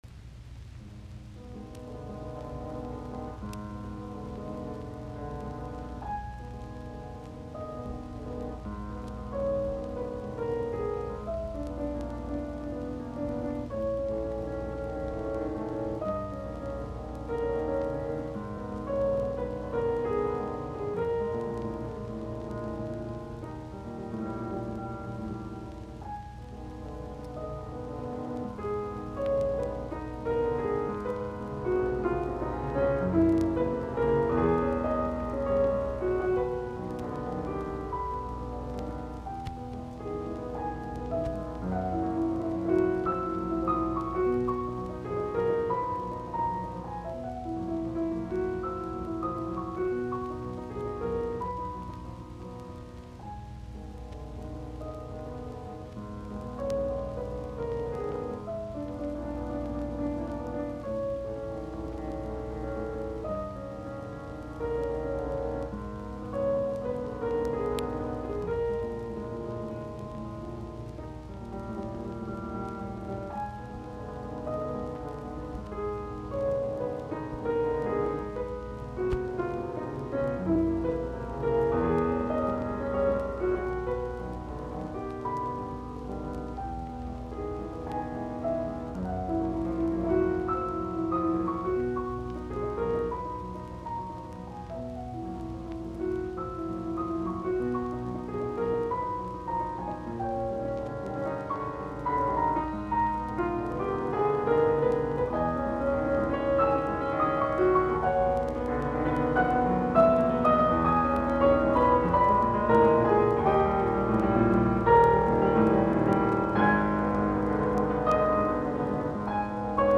Soitinnus: Piano.